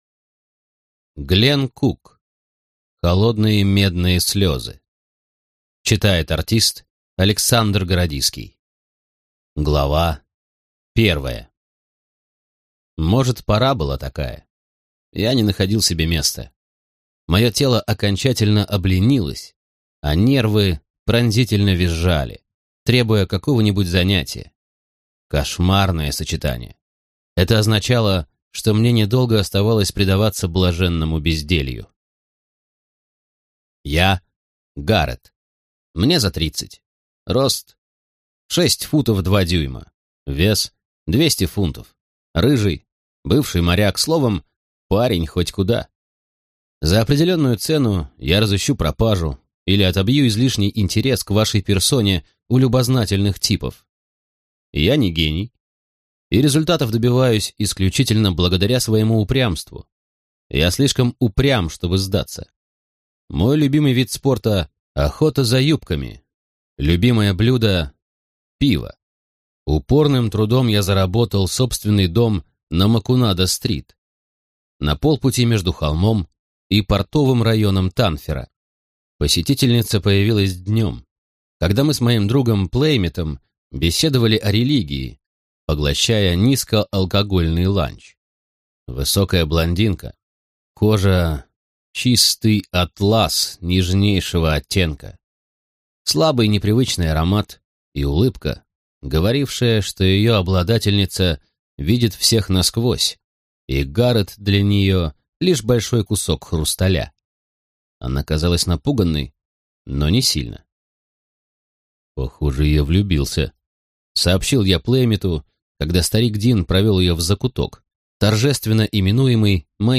Аудиокнига Холодные медные слезы | Библиотека аудиокниг
Прослушать и бесплатно скачать фрагмент аудиокниги